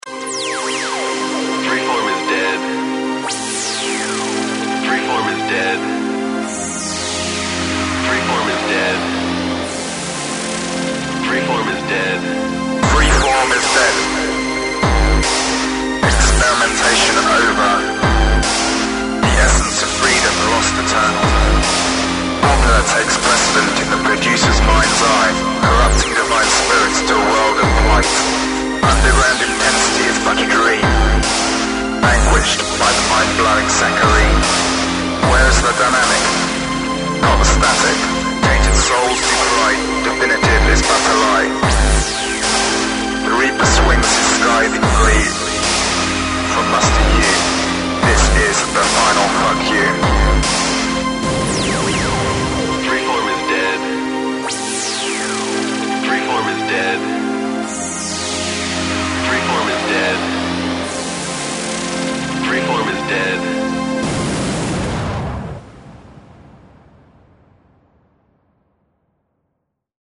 Freeform